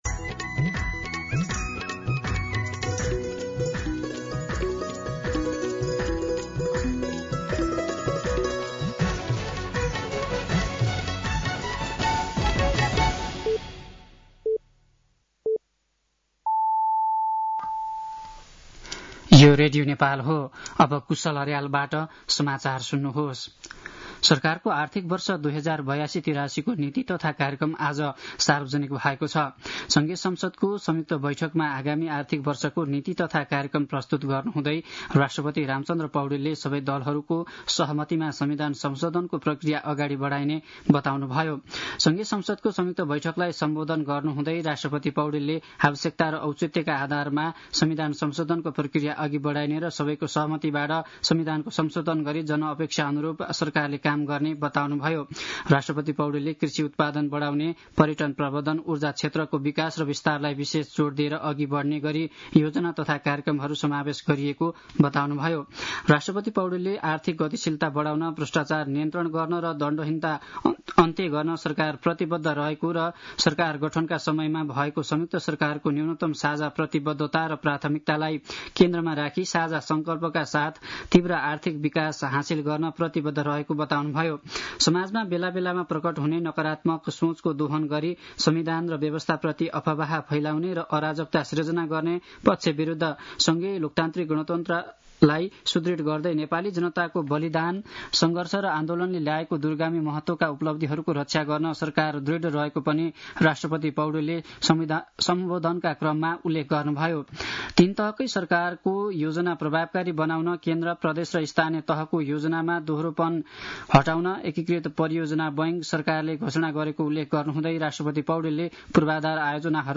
साँझ ५ बजेको नेपाली समाचार : १९ वैशाख , २०८२